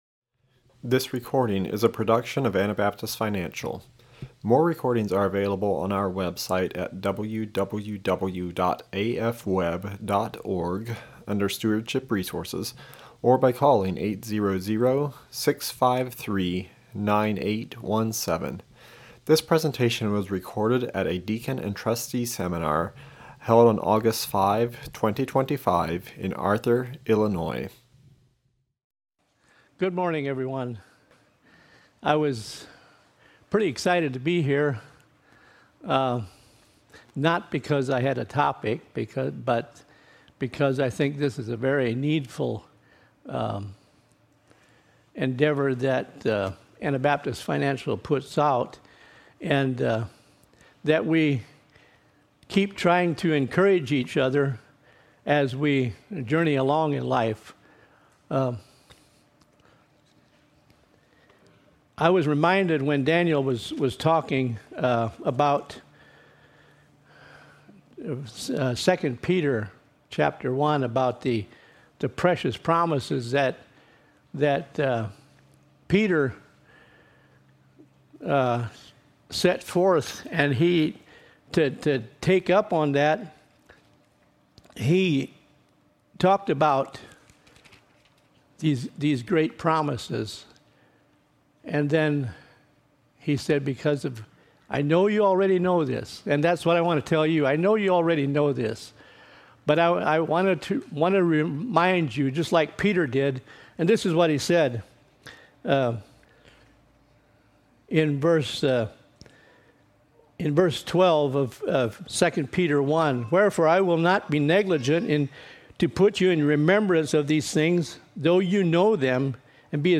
This is a recording from the Seminar for Deacons, Financial Advisors, and Trustees held in Arthur, IL in 2025.